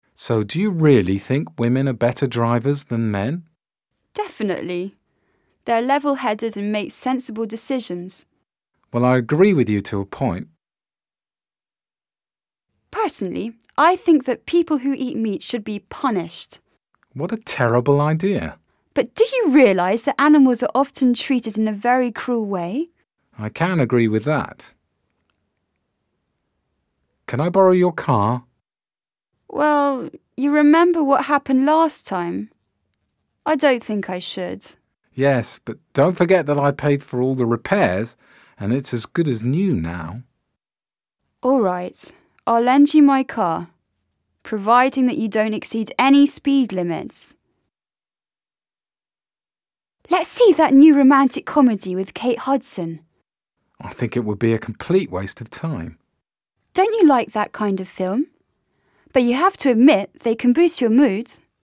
M5_u5_l2_dialogues.mp3